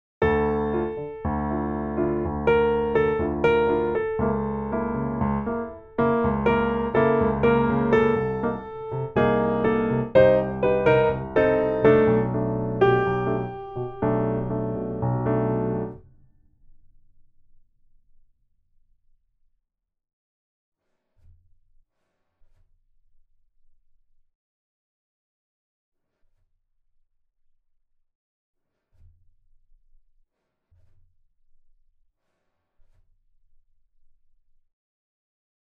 Step 3: play the melody with bass line and chords